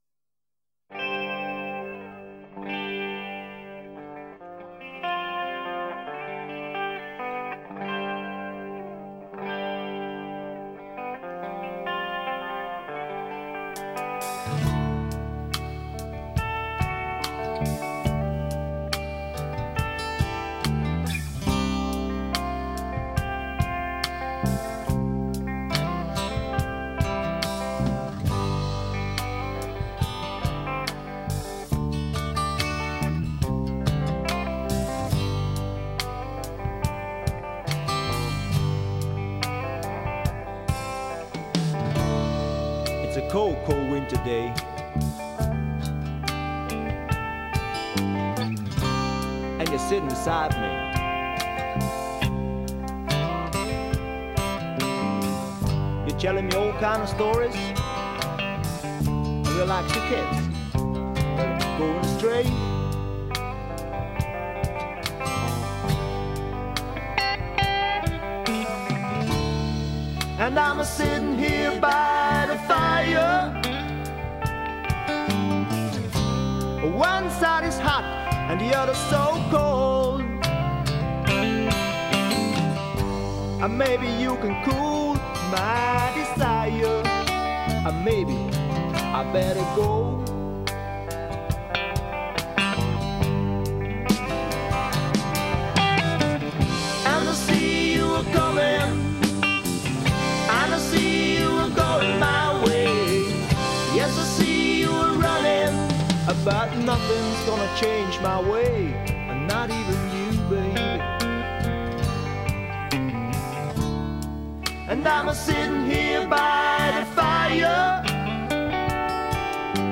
Rock'n'roll - das tut wohl